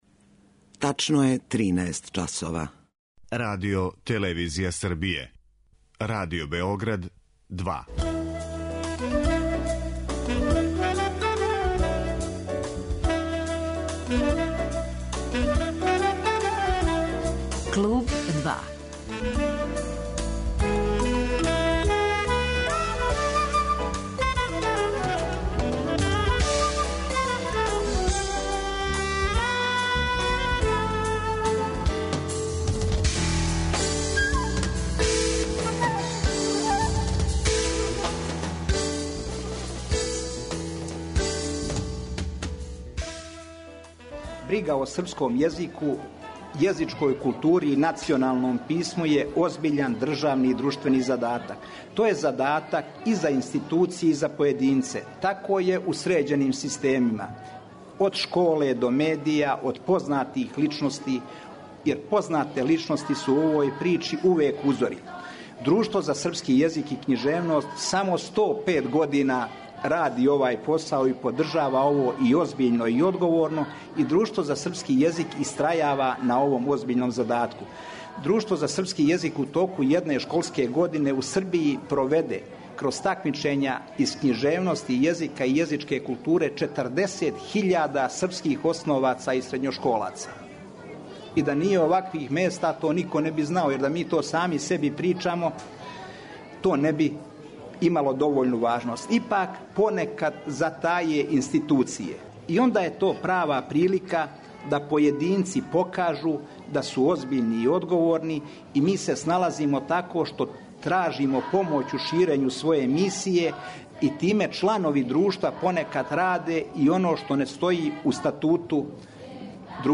Гост емисије биће иницијатор ове кампање за унапређивање језичке културе и писмености, градски секретар за културу, Владан Вукосављевић.